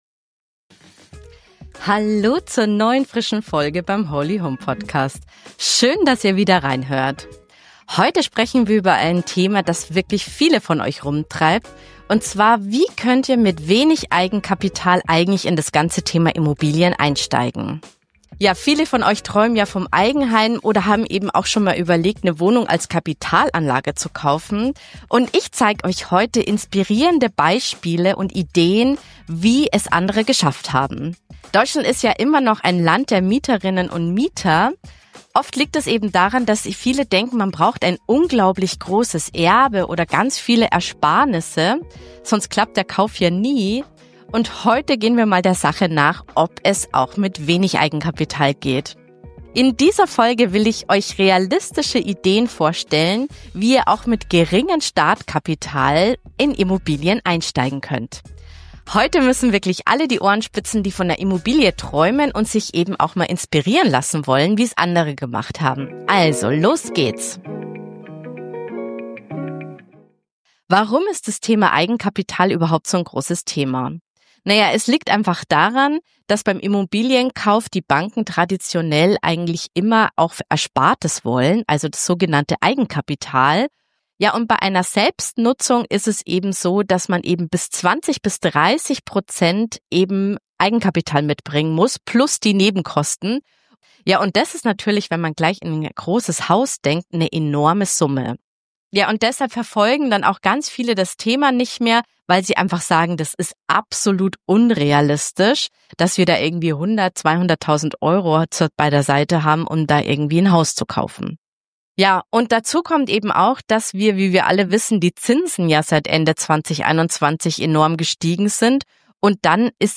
WIEDERHÖREN: Eure Lieblingsfolge: Wieviel Wohnfläche braucht eine Familie wirklich? Interview